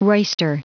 Prononciation du mot roister en anglais (fichier audio)
Prononciation du mot : roister